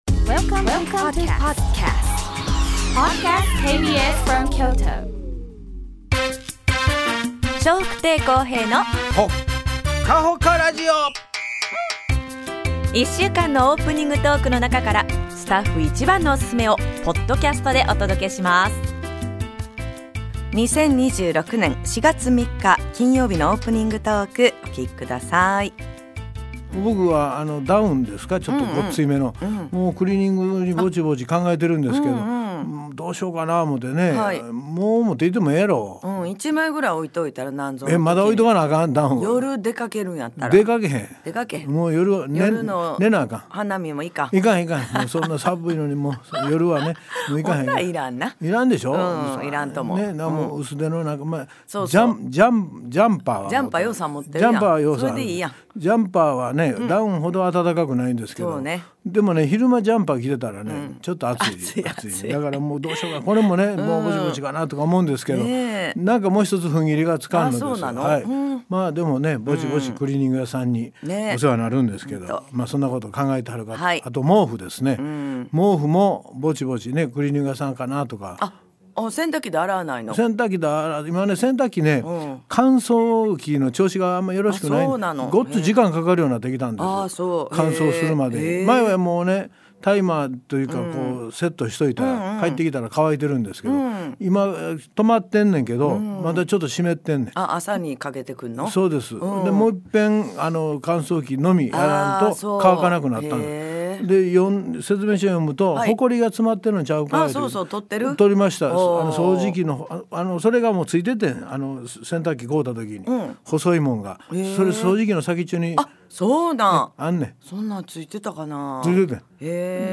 2026年4月3日のオープニングトーク
それでは、本日もお二人のやりとりをお楽しみ下さい！